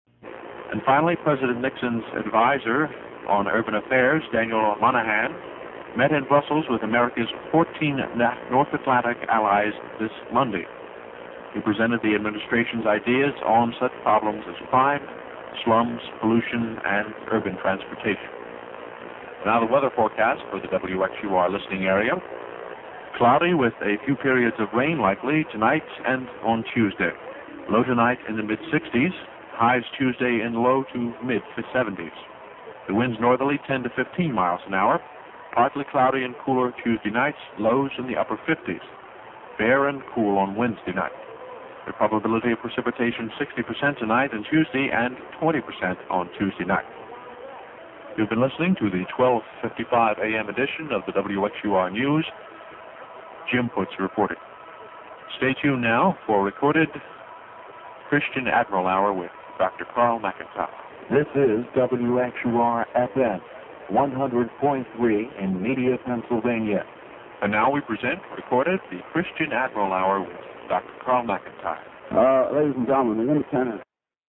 While the quality is not up to broadcast standards, it is the only copy available.